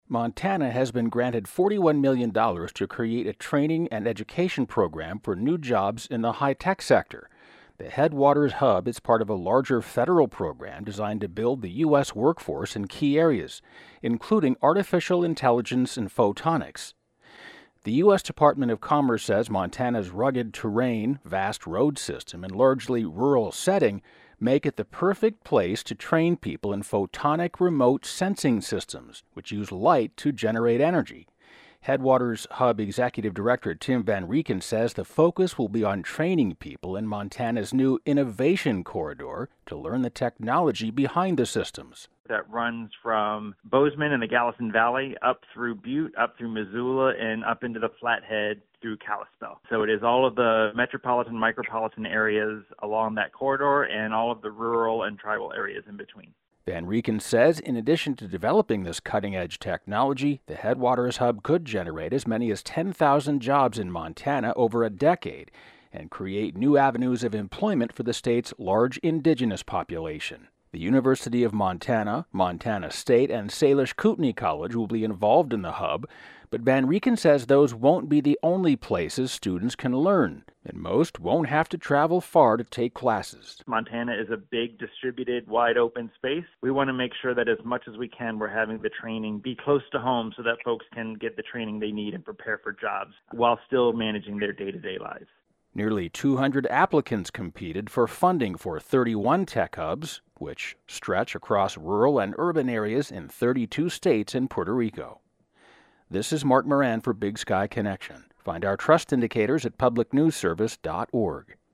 (Additional pronouncers: Salish, "SAY-lish;" Kootenai, "KOOT-nee.")